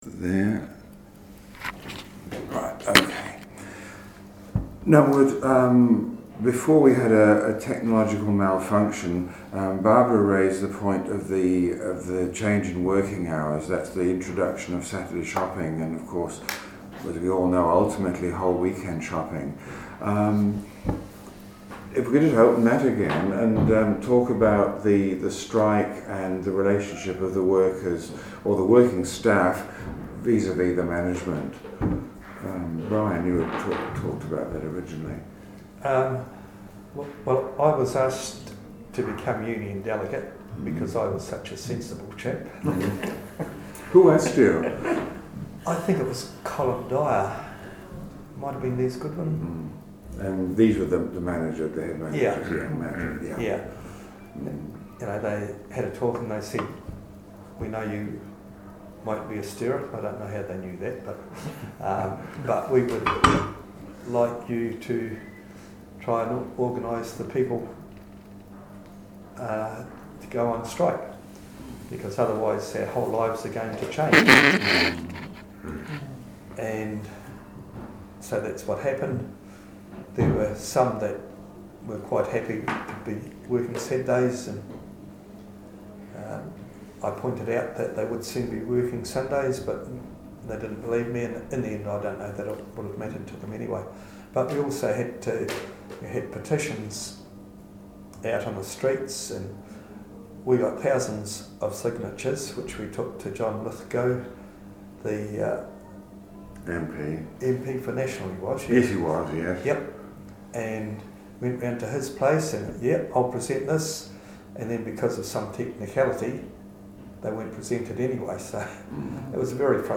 Oral Interview - Past Employees of the PDC Department Store, part 3 - Manawatū Heritage
Location: Wharite Room, P.N. City Library. Notes: Recording slightly compromised by acoustics of the room and varying distance of interviewees from recorder. ABSTRACT: Recording 3 of 3 [some material revisited] 00.00 Weekend shopping and Strike.